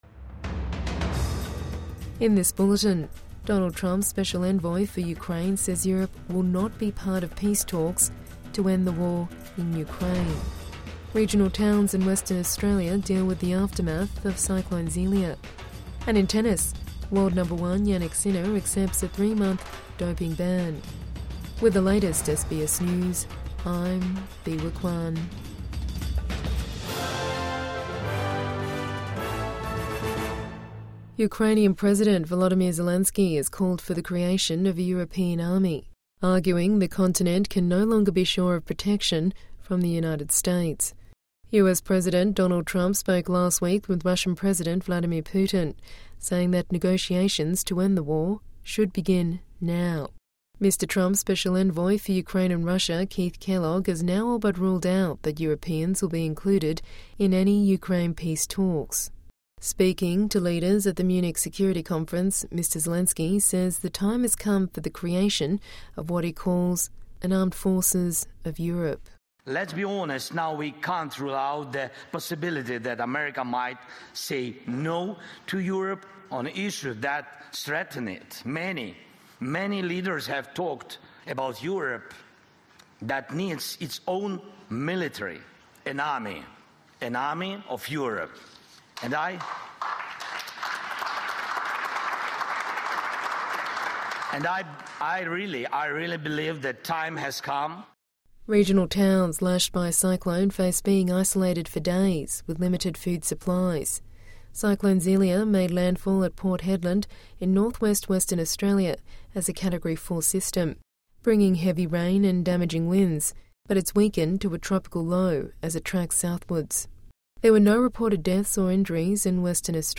Morning News Bulletin 16 February 2025